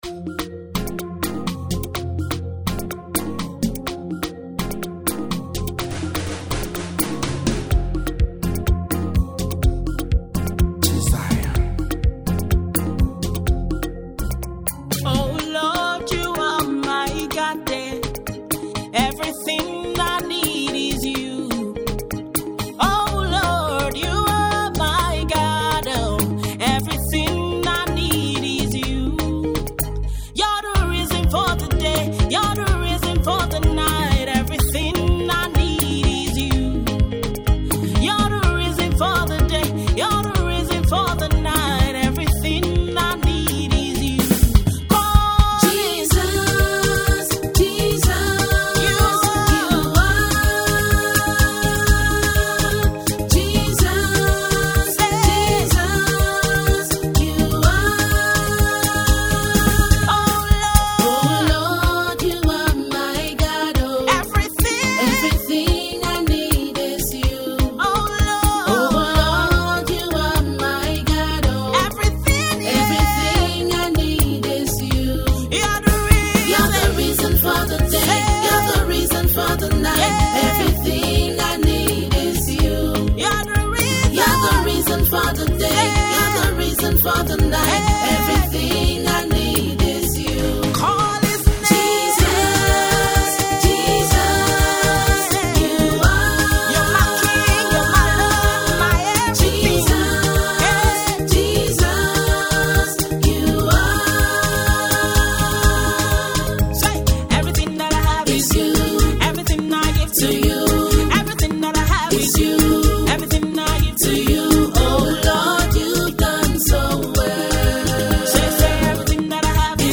gospel
praise song
This Afro-pop song was produced by one of the finest Lagos